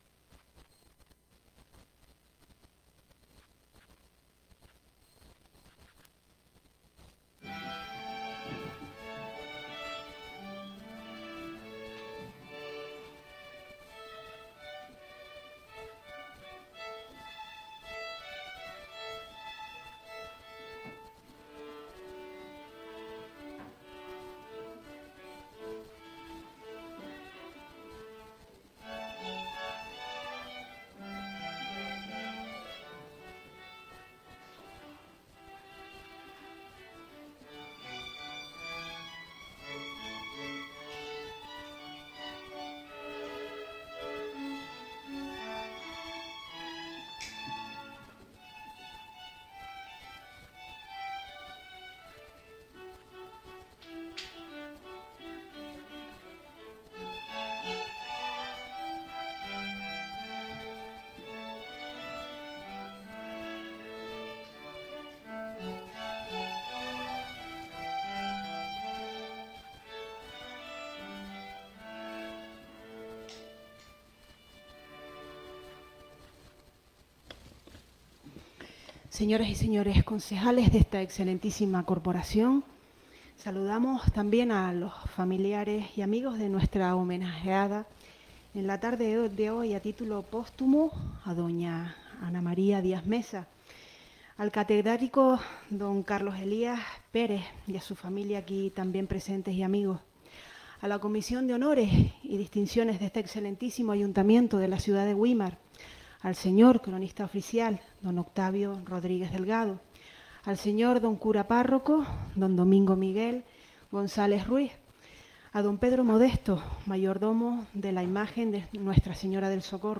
Audios de los Plenos Ordinarios y Extraordinarios del Ayuntamiento.